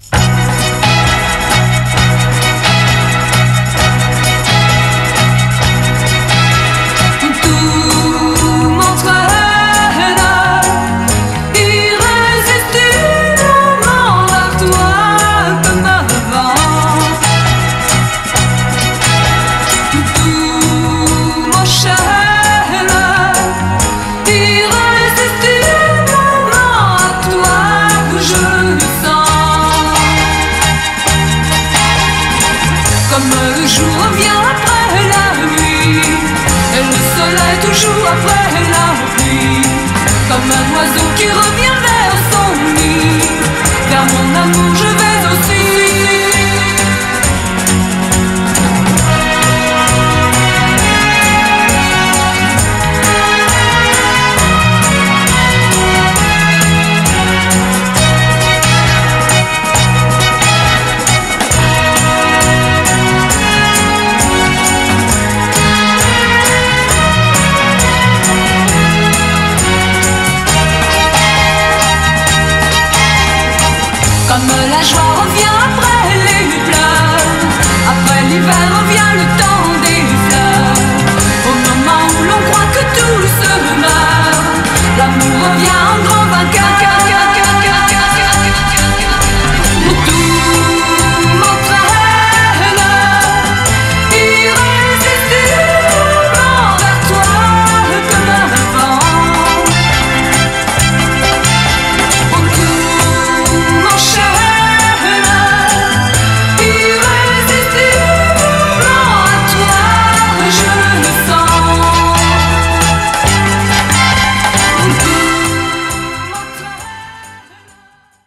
BPM129-136
Audio QualityCut From Video